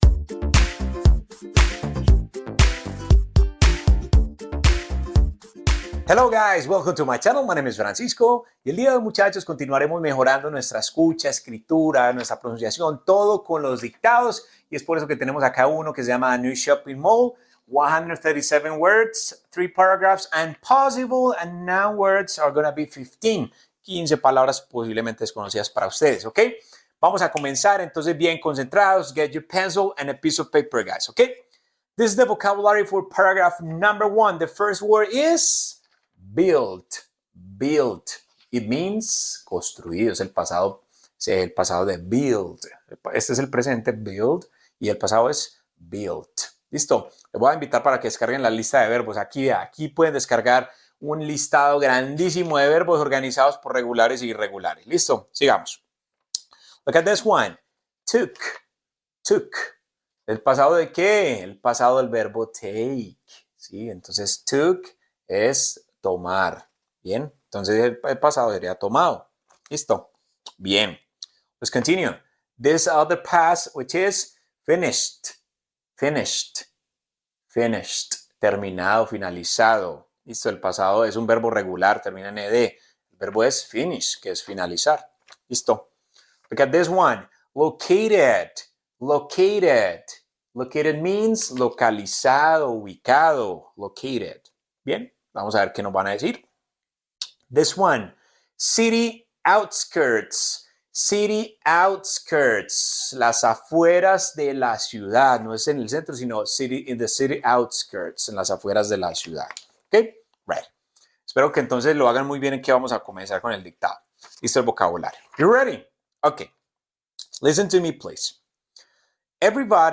Dictado corto para dominar “There is / There are” y subir tu nivel sin esfuerzo